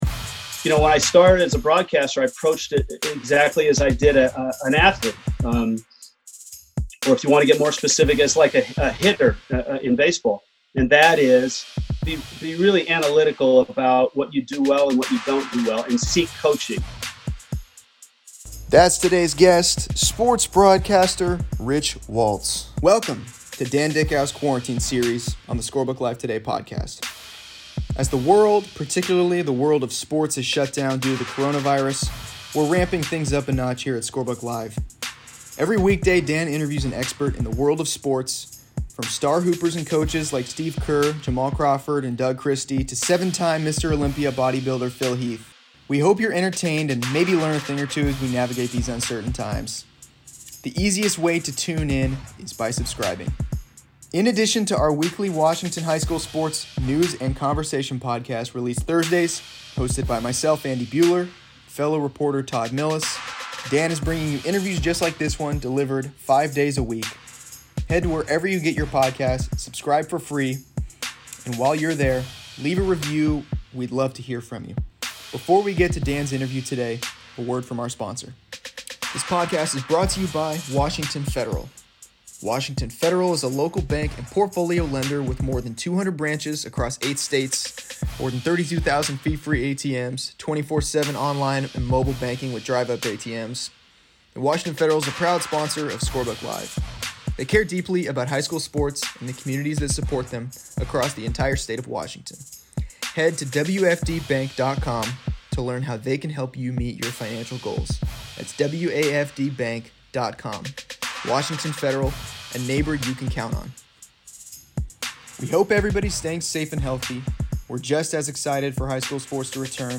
Each weekday, Dickau releases an interview with a wide range of experts in the world of sports. On Monday, sports broadcaster Rich Waltz joins to talk about his path into the professions and the top sports moments he's ever witnessed.